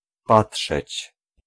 Ääntäminen
Vaihtoehtoiset kirjoitusmuodot patrzyć Ääntäminen Haettu sana löytyi näillä lähdekielillä: puola Käännös Ääninäyte Verbit 1. look at 2. look US Esimerkit Mąż patrzy na swoją żonę.